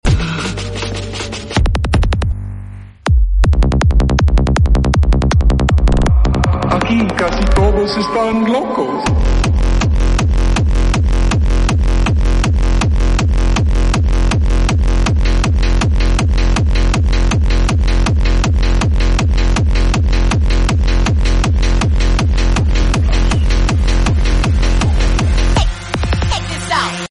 👁 Visual Rave Party Dance Sound Effects Free Download